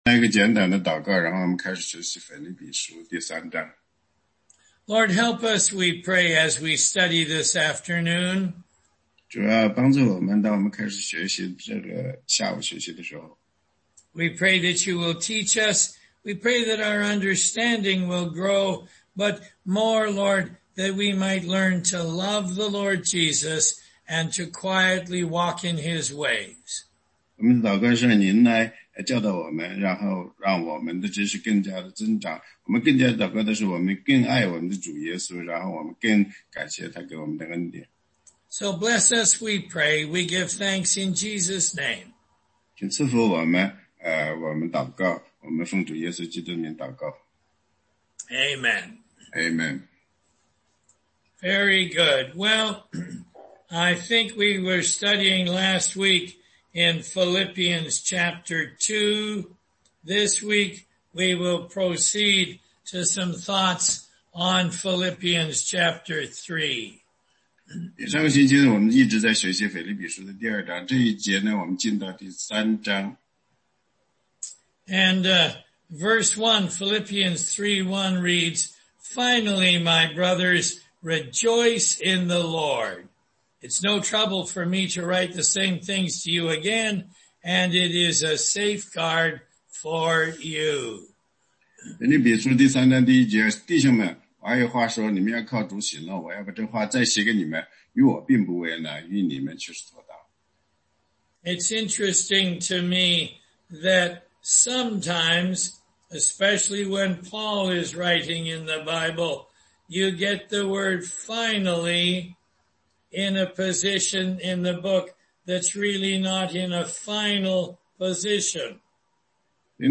16街讲道录音 - 腓立比书3章1-21节